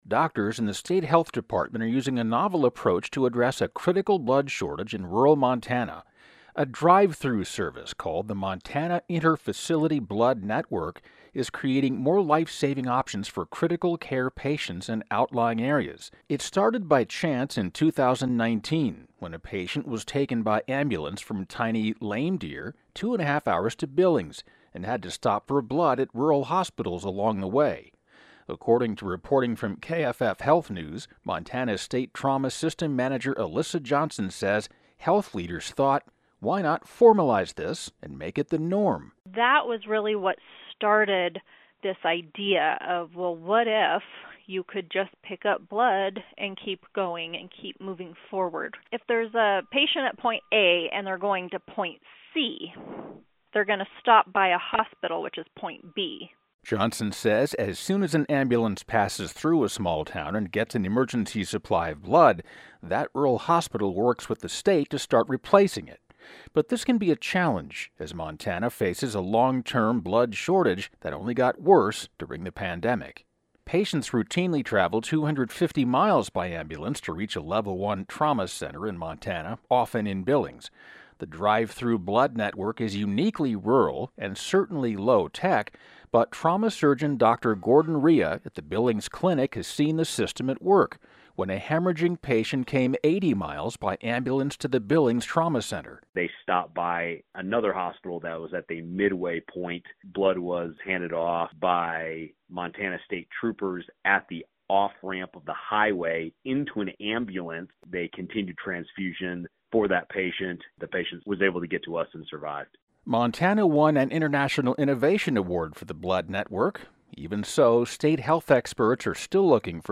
Broadcast version